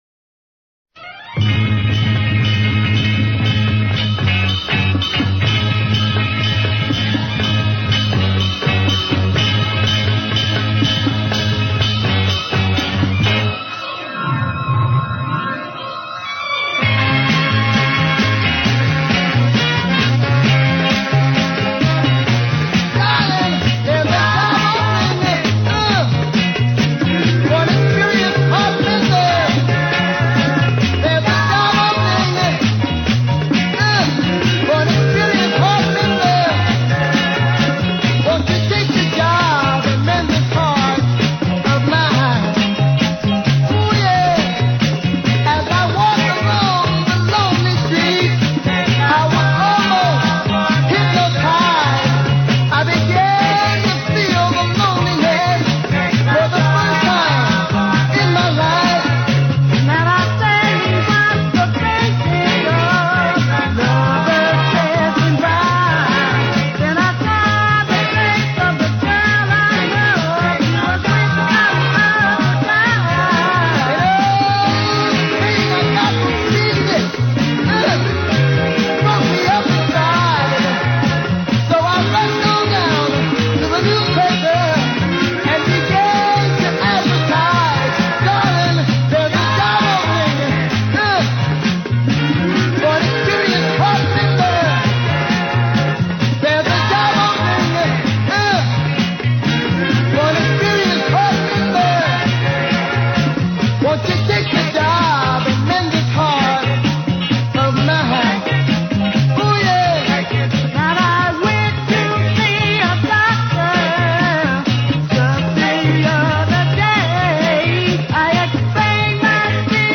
Northern Soul